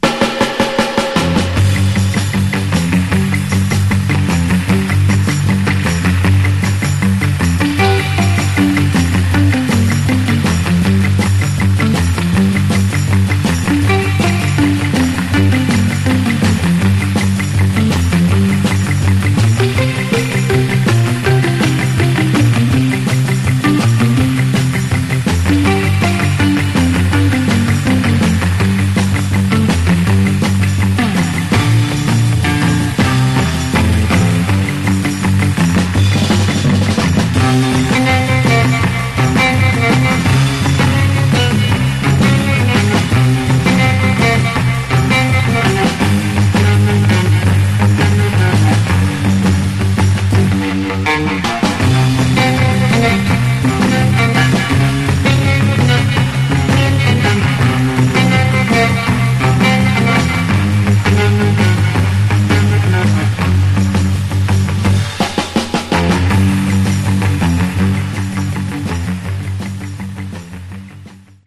Genre: Surf/Cars